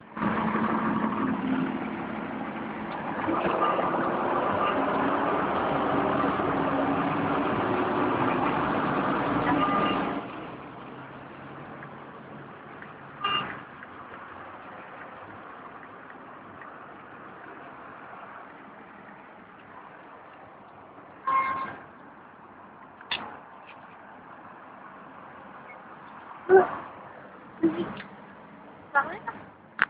bibliothèque
bruits de cars